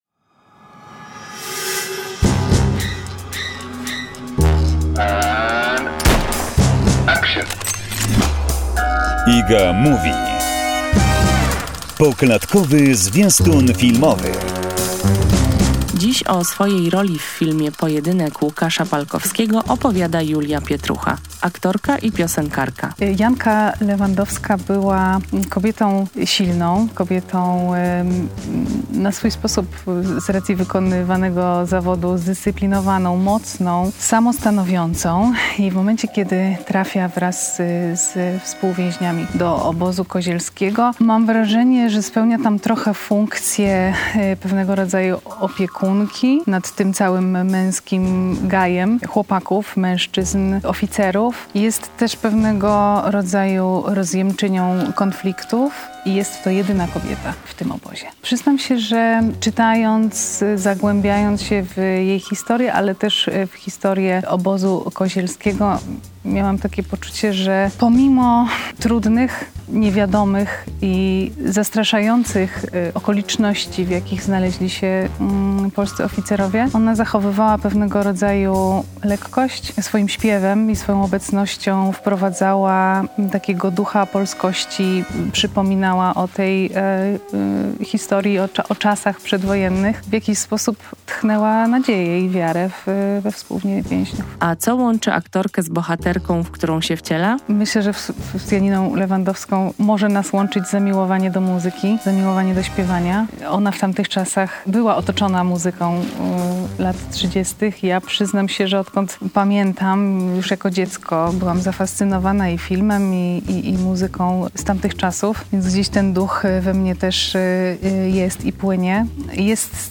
rozmowa z Julią Pietruchą